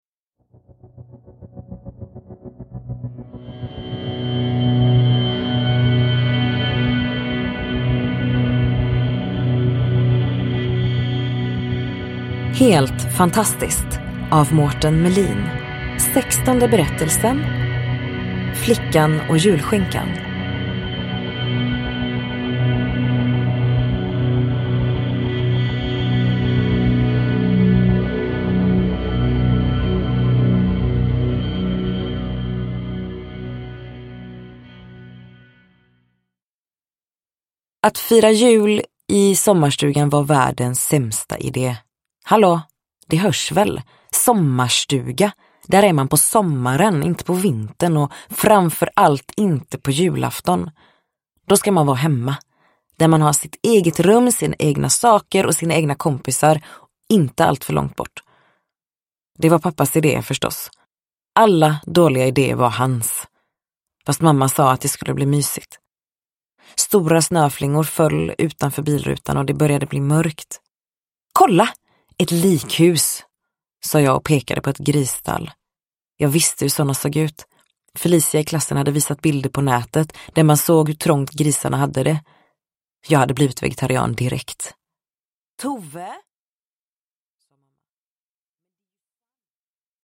Flickan och julskinkan : en novell ur samlingen Helt fantastiskt – Ljudbok – Laddas ner